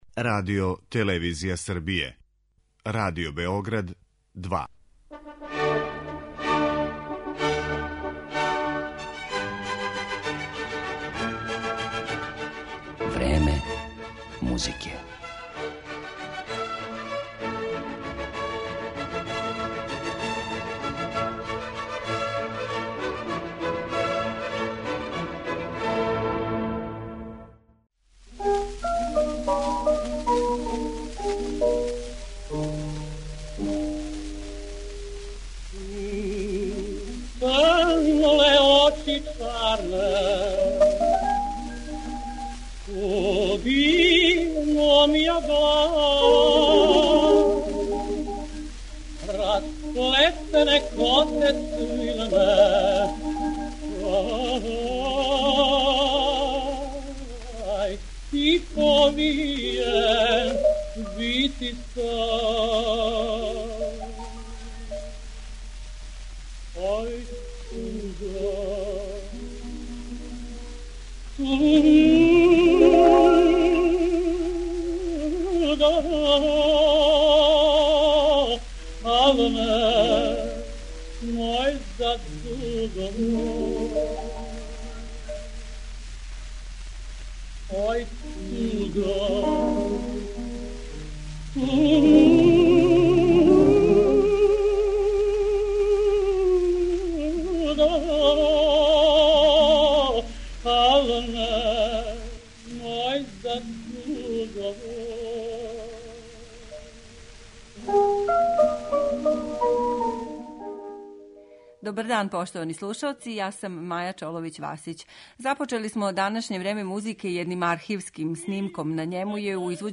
Његов опус ћемо осим поменутим композицијама данас представити и хорским и оркестарским композицијама, као и соло песмама.